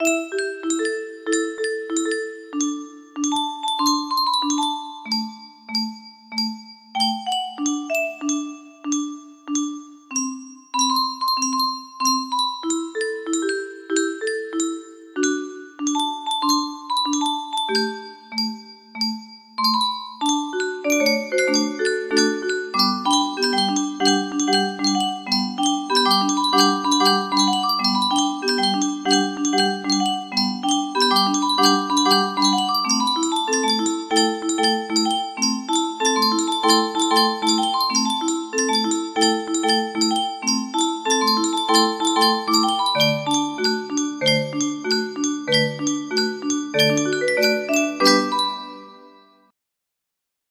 Musique music box melody